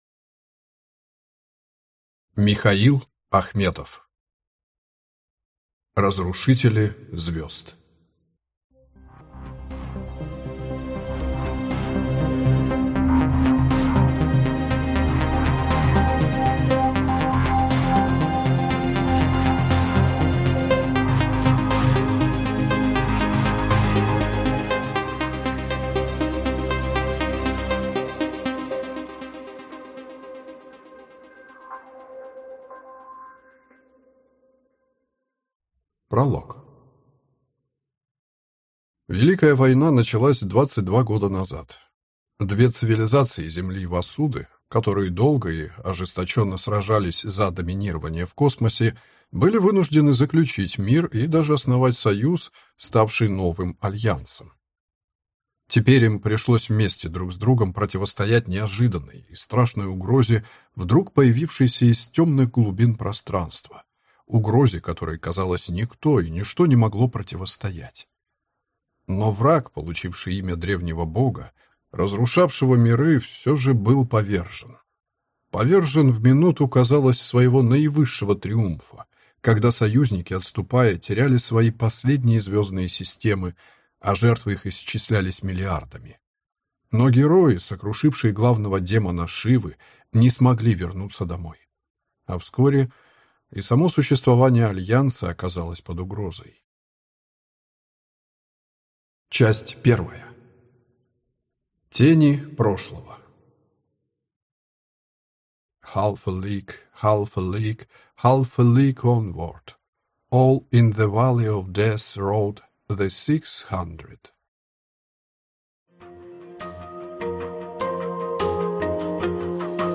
Аудиокнига Разрушители звезд | Библиотека аудиокниг